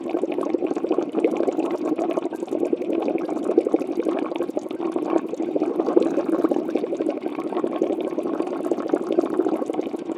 water_bubbling_03_loop.wav